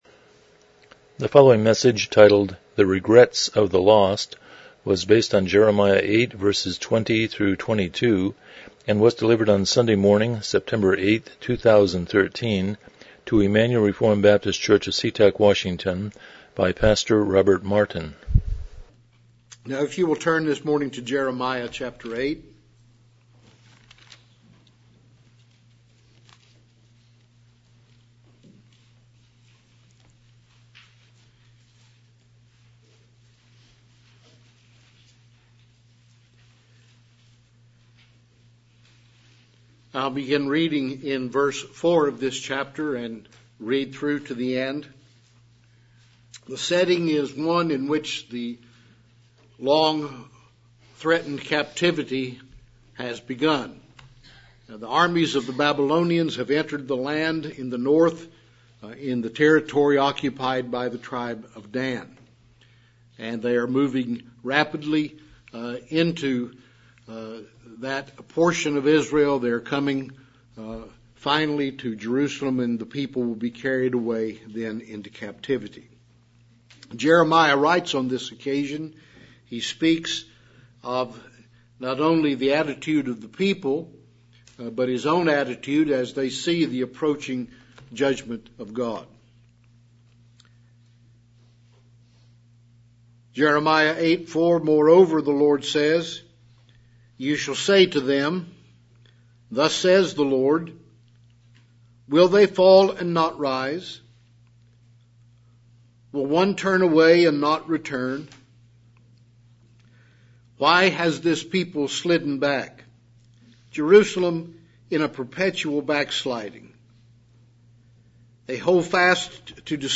Passage: Jeremiah 8:20-22 Service Type: Morning Worship